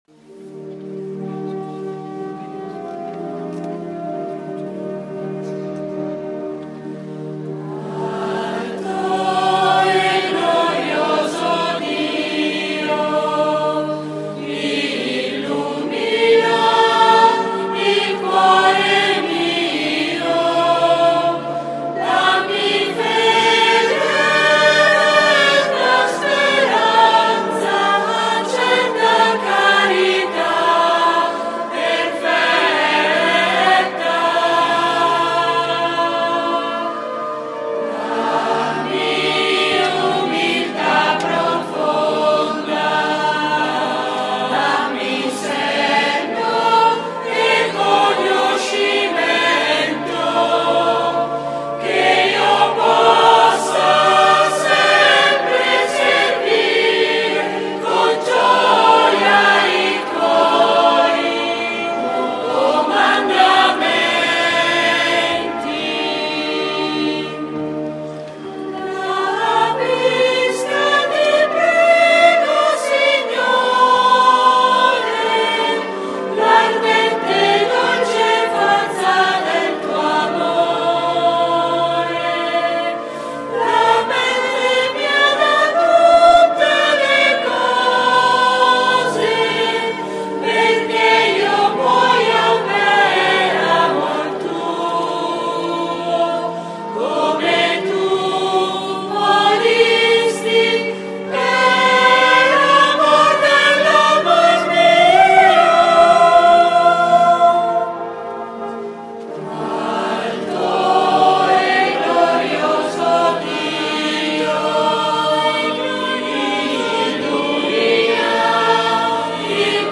10 -III di QUARESIMA (Dio, voi non lo conoscete)